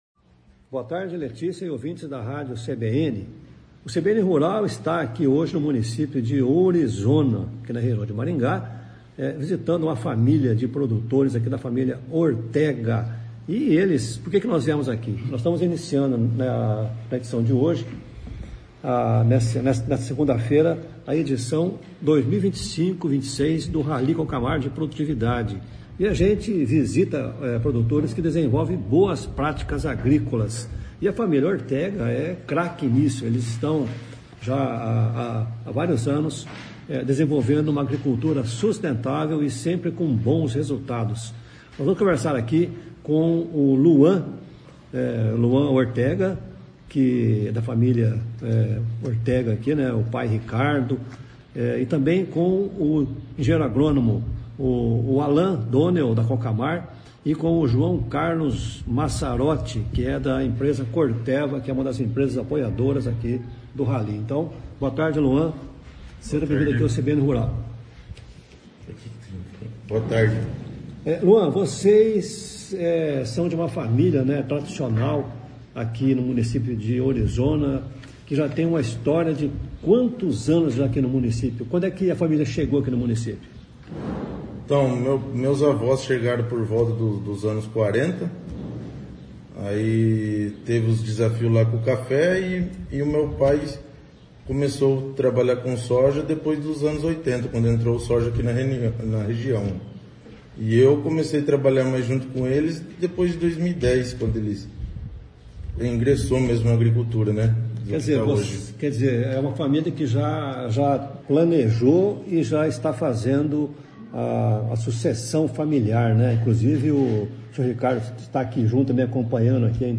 O CBN Rural se deslocou até o município de Ourizona, na região de Maringá, para acompanhar o início do Rally Cocamar